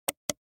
button-click.mp3